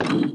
BigButtonPress.wav